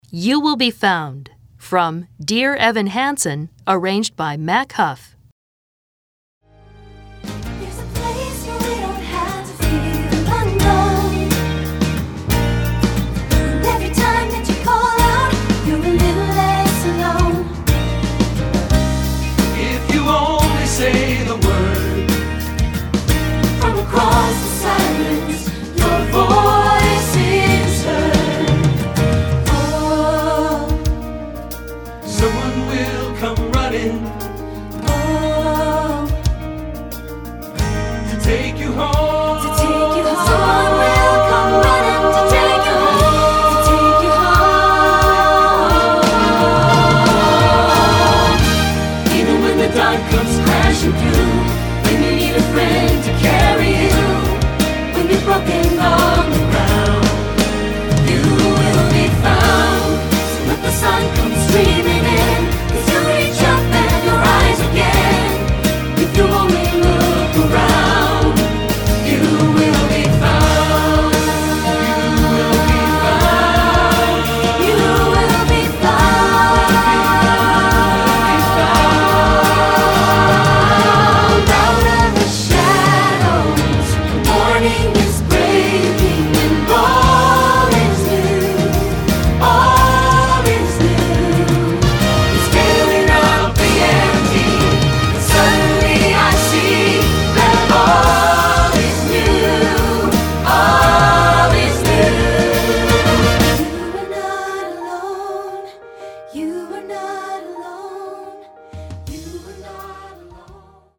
Choral Movie/TV/Broadway
SSA